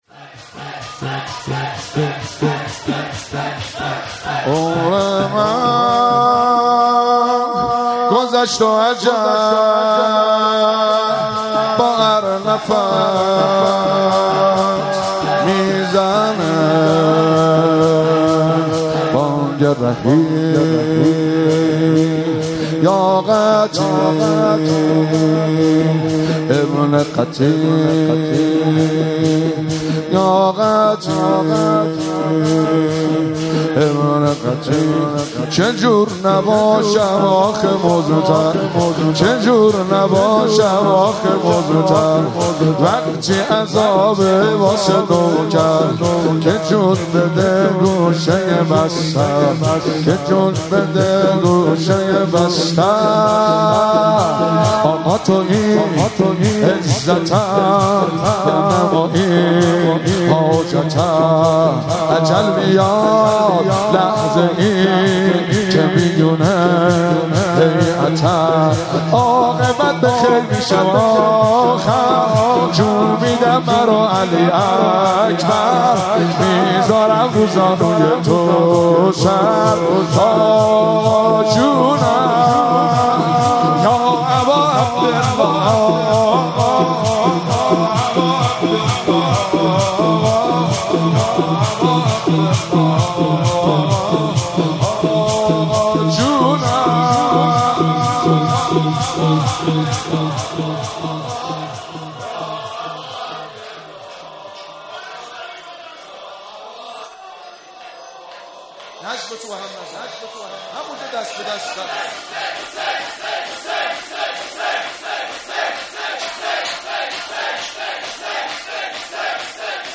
مراسم شهادت حضرت رقیه (س)- شهریور 1401
مداحان: